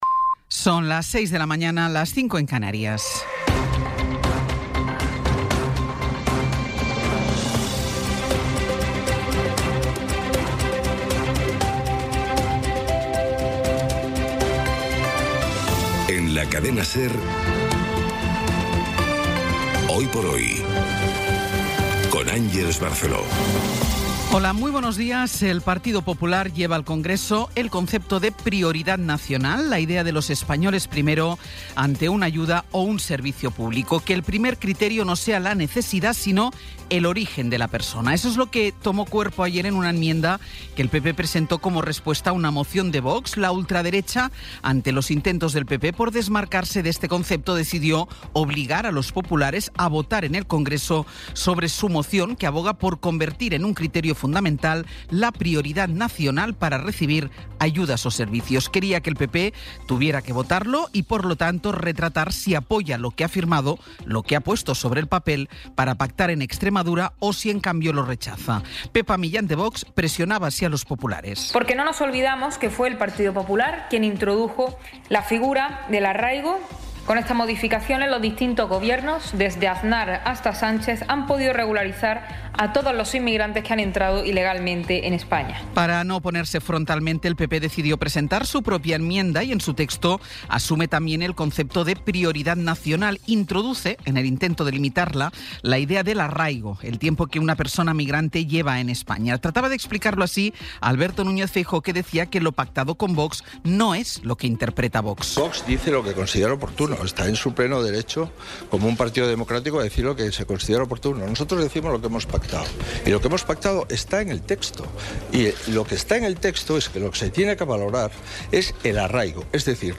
Resumen informativo con las noticias más destacadas del 22 de abril de 2026 a las seis de la mañana.